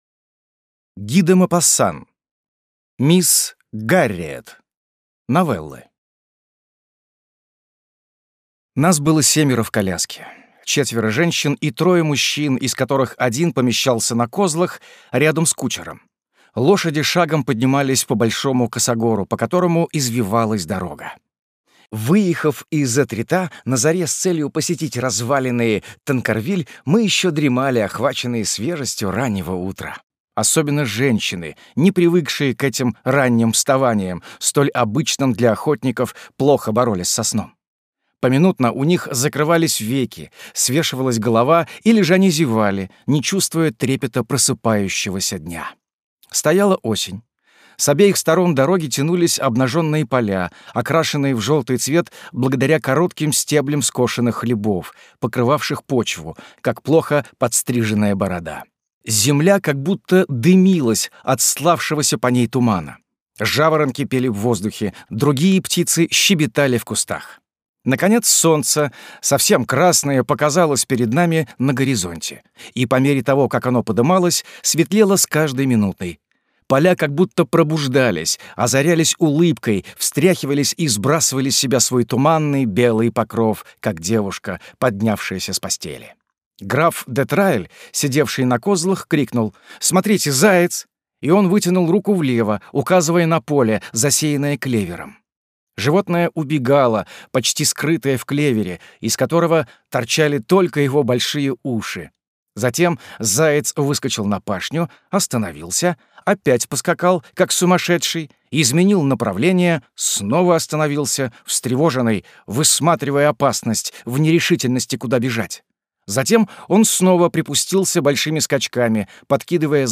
Аудиокнига Мисс Гарриет. Новеллы | Библиотека аудиокниг